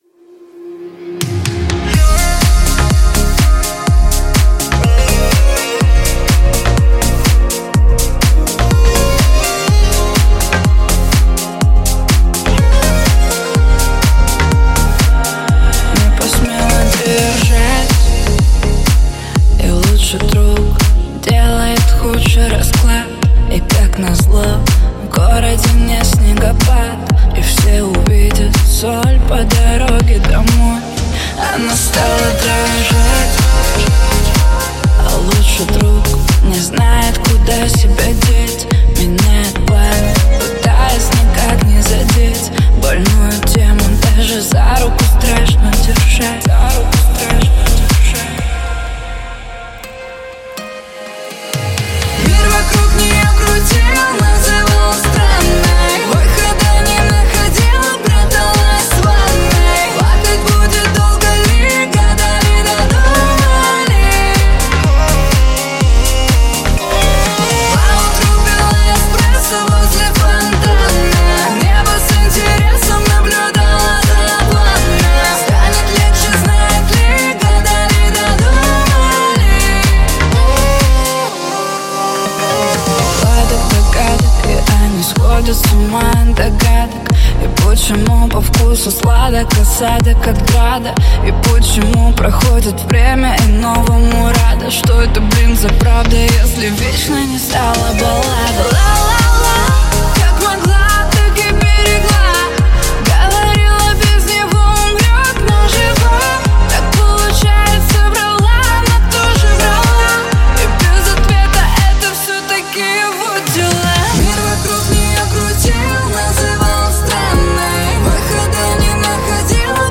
Поп-музыка
• Жанр песни: Жанры / Поп-музыка